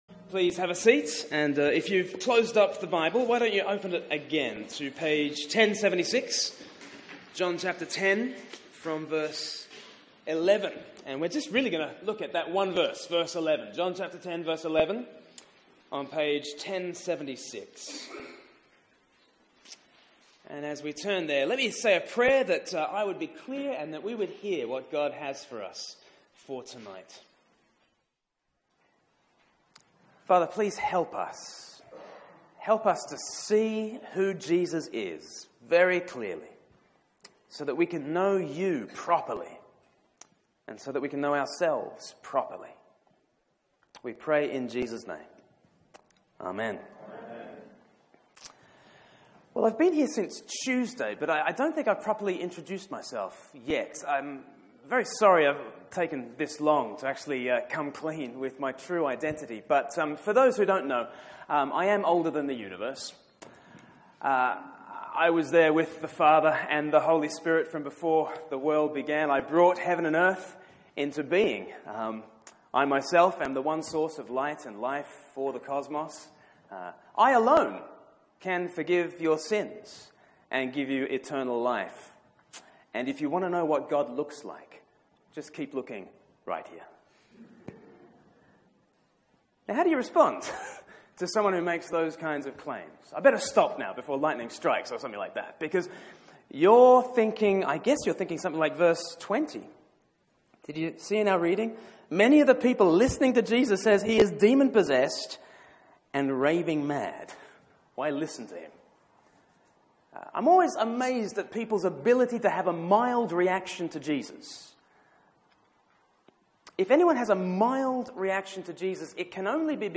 Media for 6:30pm Service on Sun 23rd Apr 2017 18:30 Speaker
Sermon